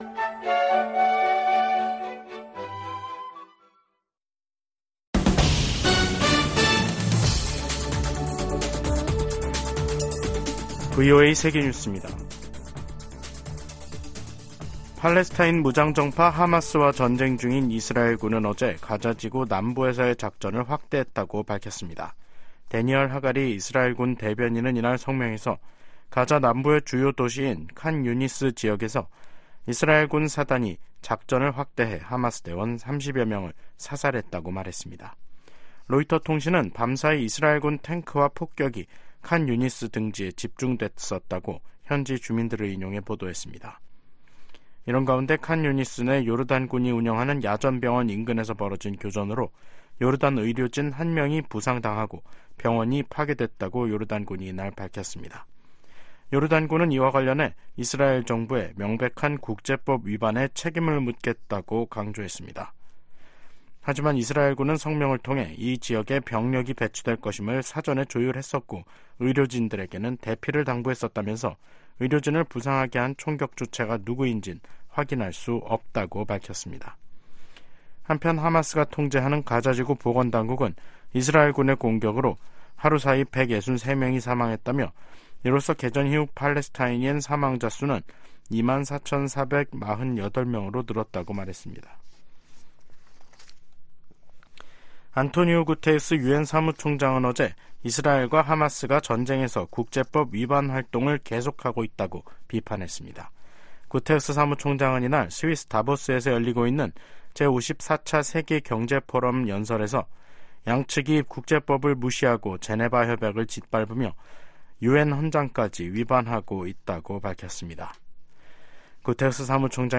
세계 뉴스와 함께 미국의 모든 것을 소개하는 '생방송 여기는 워싱턴입니다', 2024년 1월 18일 저녁 방송입니다. '지구촌 오늘'에서는 파키스탄이 이란 내 목표물을 공습한 소식 전해드리고, '아메리카 나우'에서는 조 바이든 대통령과 의회 지도부가 우크라이나 지원을 위한 예산안 담판을 위해 회동했지만 입장차를 좁히지 못한 이야기 살펴보겠습니다.